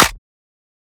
edm-clap-53.wav